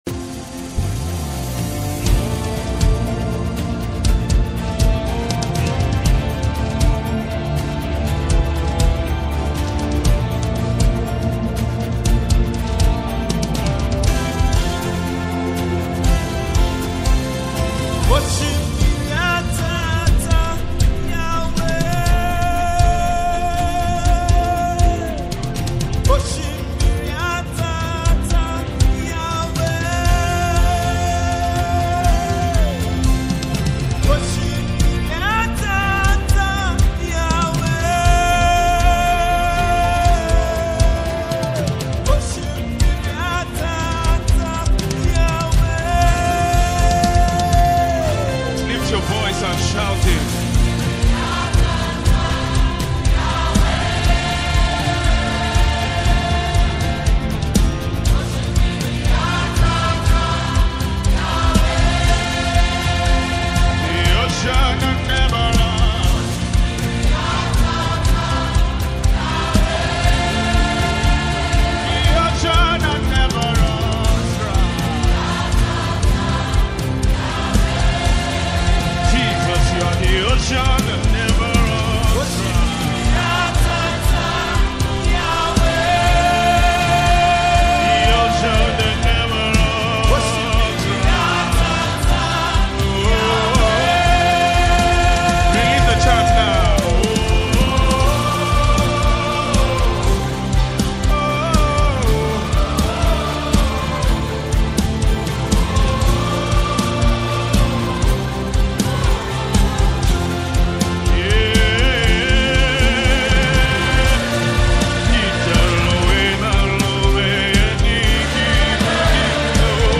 worship song